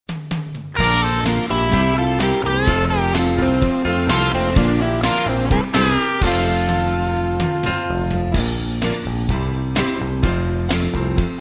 Instrumental mp3 Track